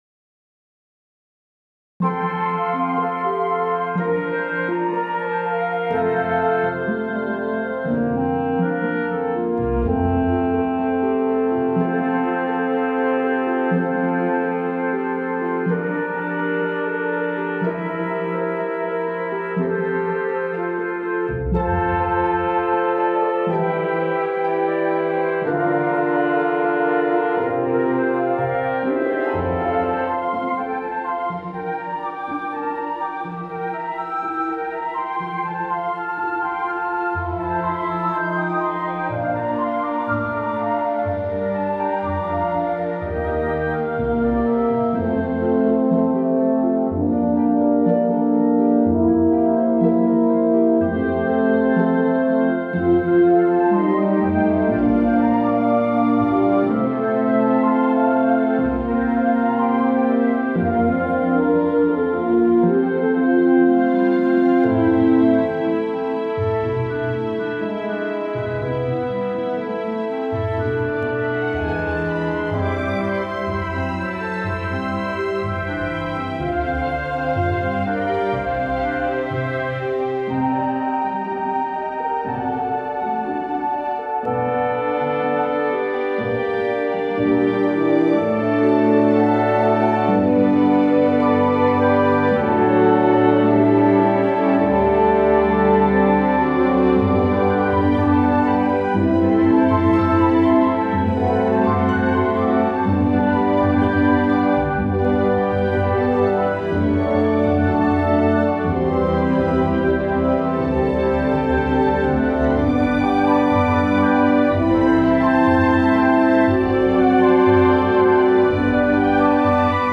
a delightful orchestral accompaniment